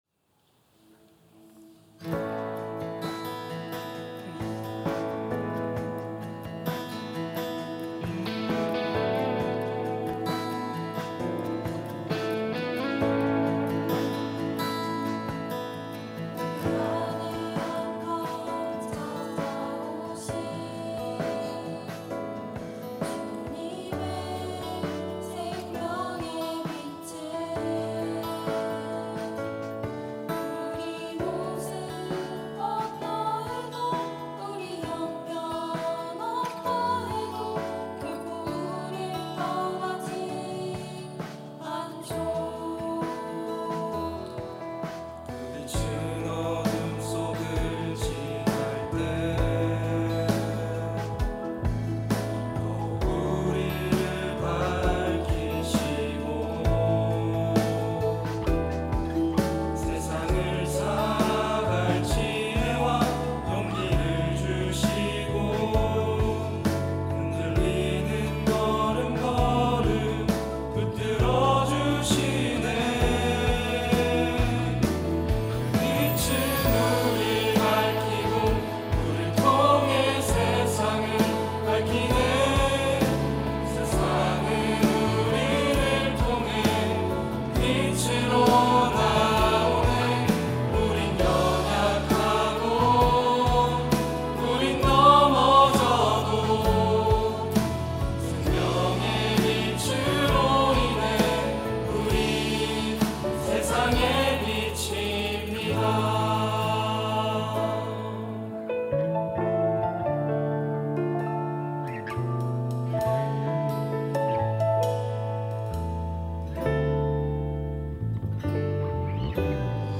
특송과 특주 - 세상의 빛
청년부 2024 임원